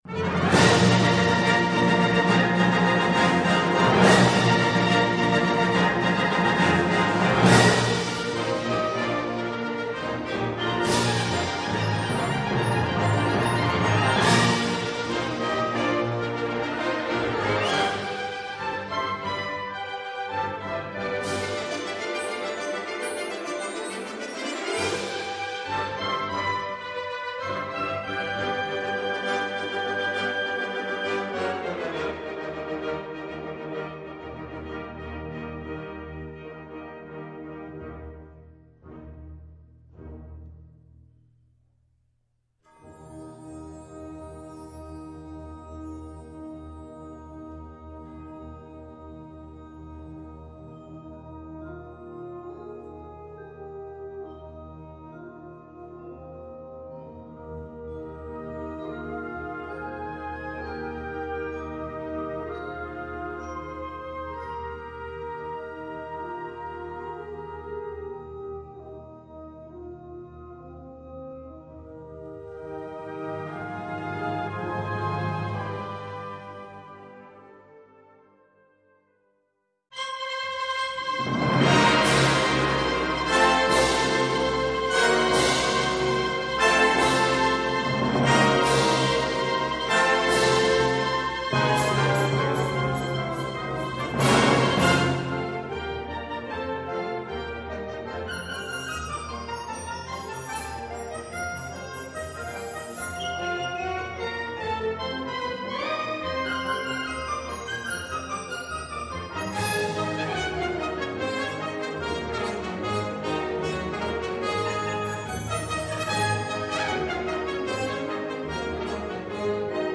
Gattung: Suite
Besetzung: Blasorchester
Dies ist Programm-Musik, wie sie nicht besser sein könnte.